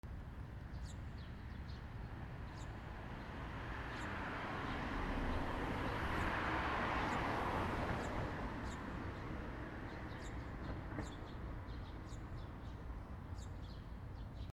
車 追い抜き 通過
/ E｜乗り物 / E-10 ｜自動車